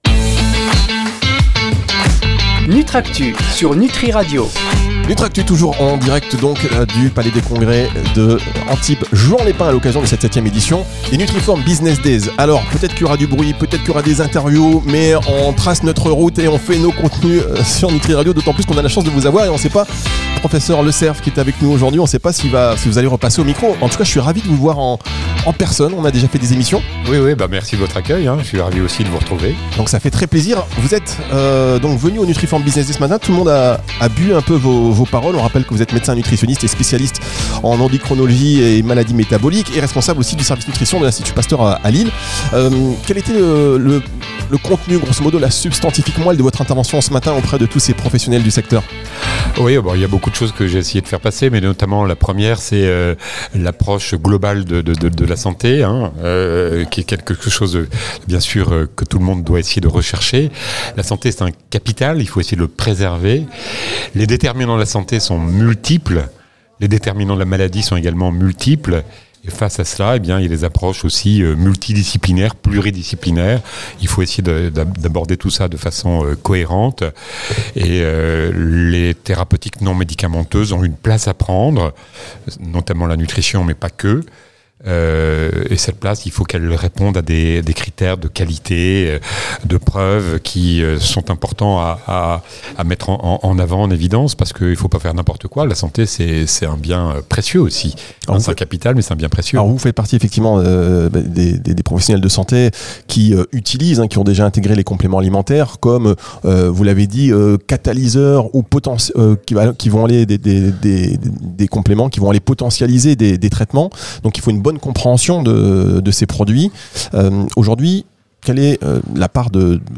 est passé au micro de Nutriradio lors de la 7em édition des NFBD.